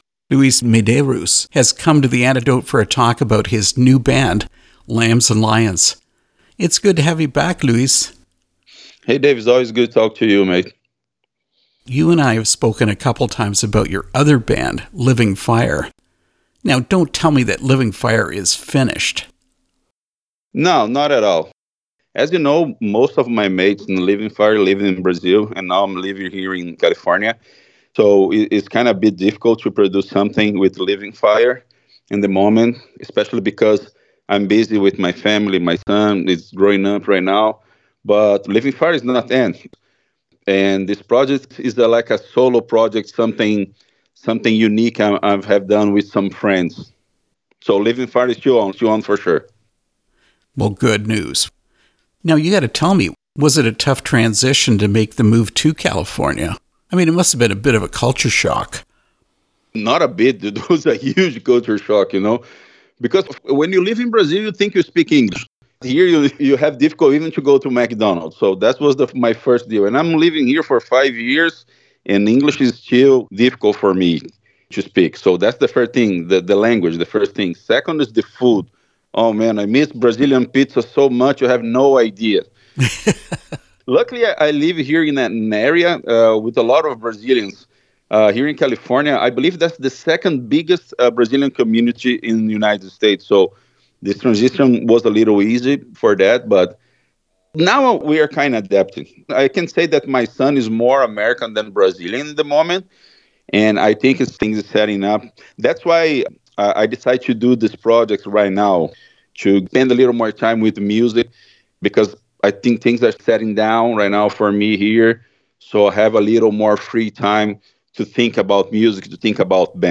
Interview with Lambs & Lions
lambs-and-lions-interview.mp3